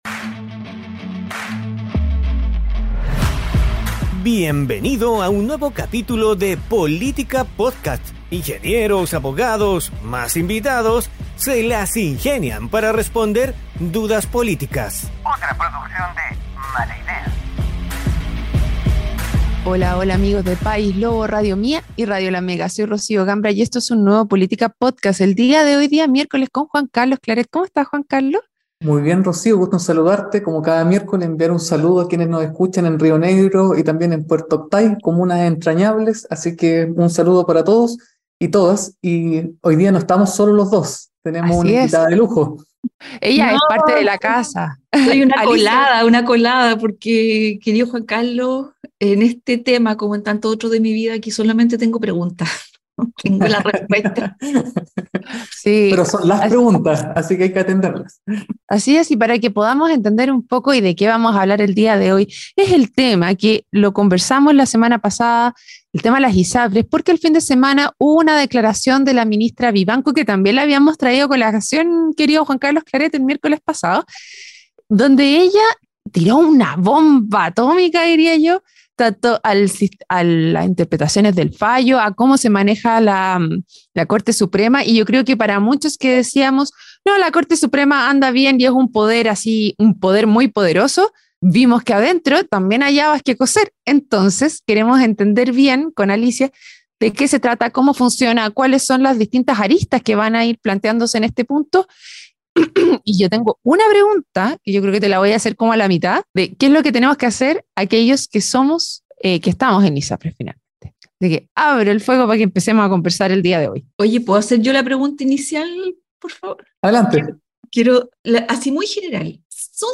programa donde junto a panelistas estables e invitados tratan de responder dudas políticas.